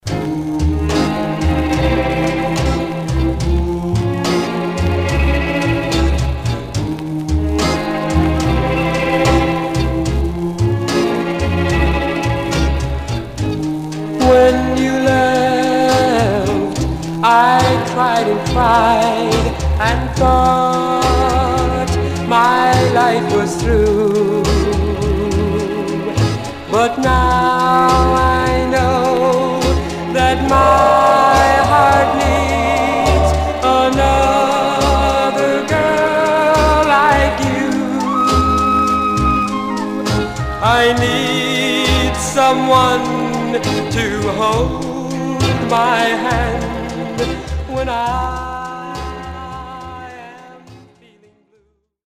Some surface noise/wear
Mono
Teen